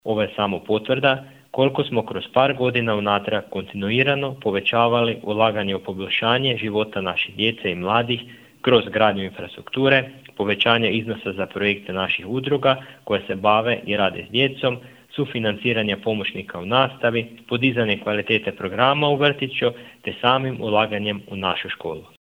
Od 15. prosinca Općina Mala Subotica nosi titulu Općina – Prijatelj djece. Nakon dvije godine predanog rada na kandidaturi, Općina je uspjela dobiti taj status, kaže načelnik Valentino Škvorc.